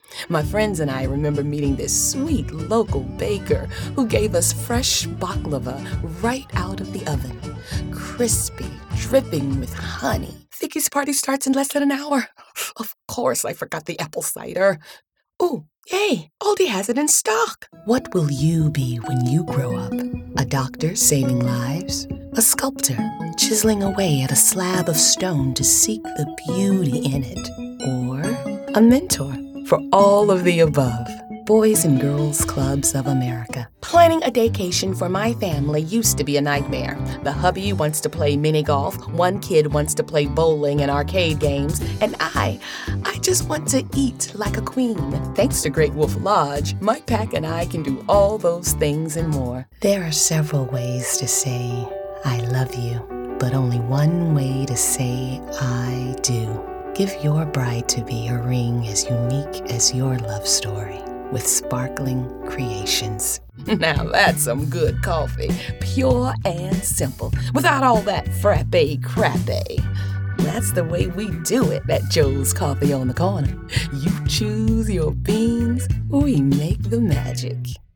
Commercial Reel